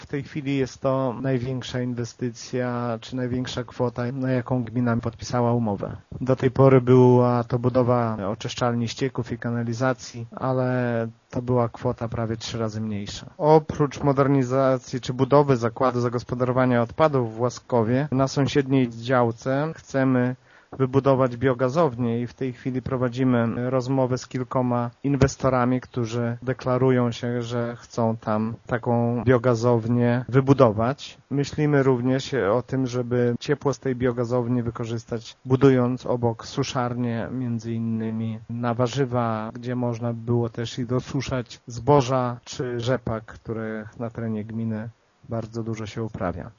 Szacuje się, że projekt i budowa zakładu w Łaskowie może kosztować około 20 milionów złotych, z czego 75 procent to dofinansowanie ze środków Regionalnego Programu Operacyjnego Województwa Lubelskiego. Takiej inwestycji w gminie Mircze jeszcze nie było, tym bardziej, że zakład zagospodarowania to nie wszystko – przyznaje wójt Szopiński: